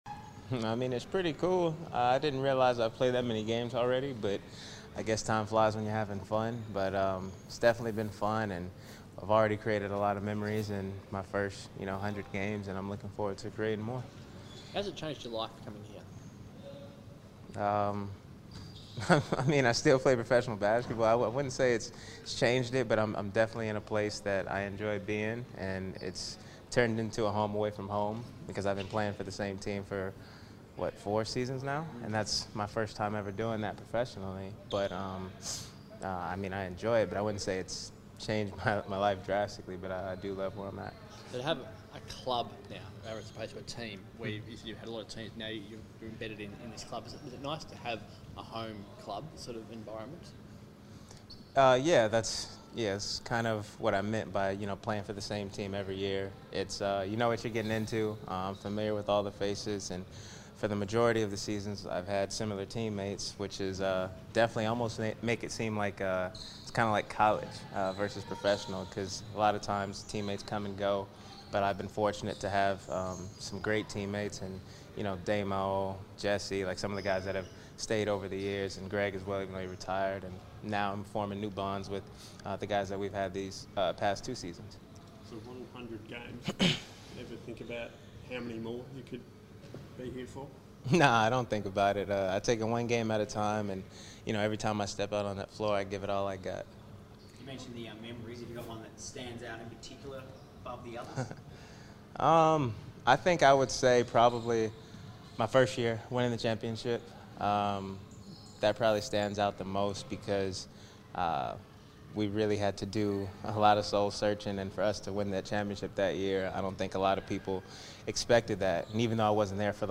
Bryce Cotton Press Conference - 15 January 2020